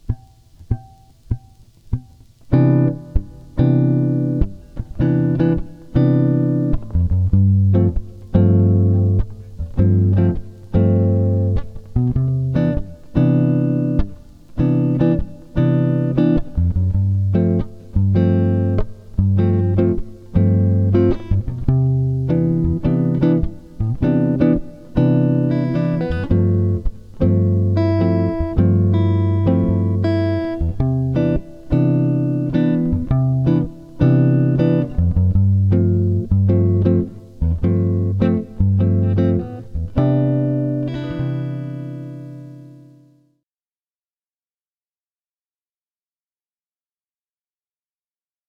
Basic backing chord tracks are below.
C Major Backing slow tempo (1.48 MB)